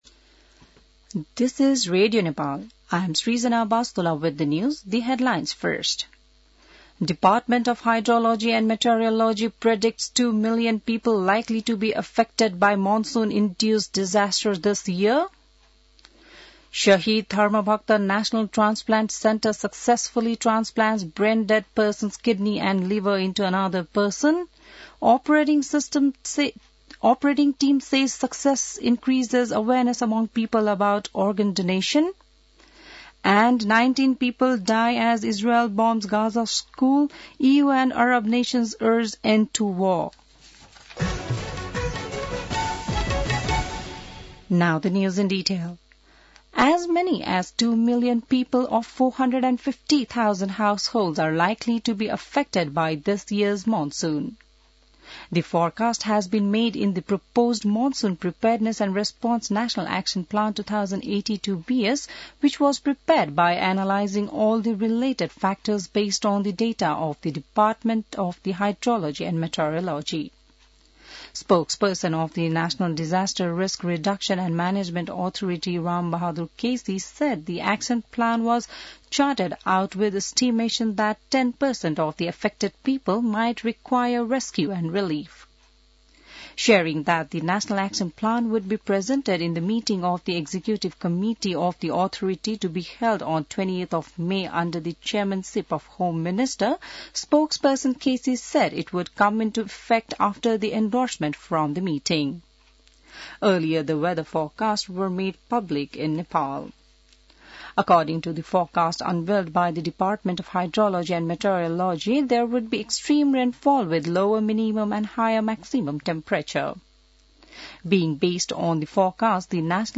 An online outlet of Nepal's national radio broadcaster
बिहान ८ बजेको अङ्ग्रेजी समाचार : १२ जेठ , २०८२